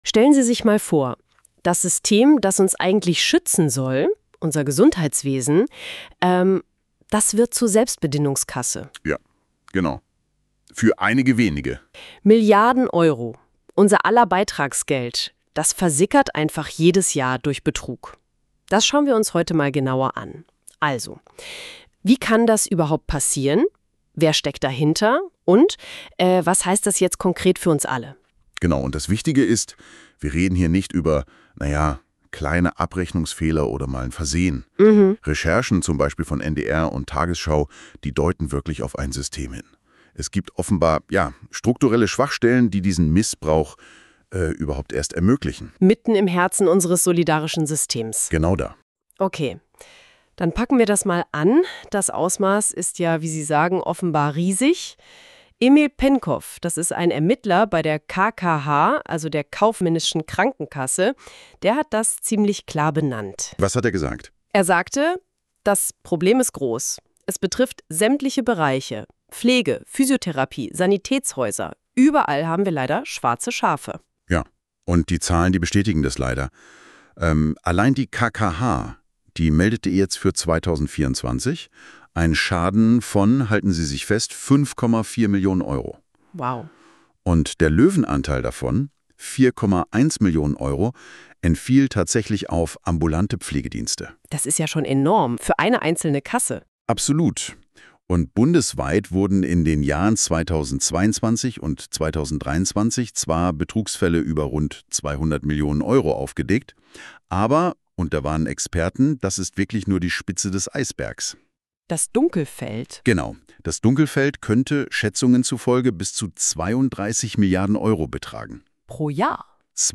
Dieser Podcast wurde mit Unterstützung von Künstlicher Intelligenz erstellt.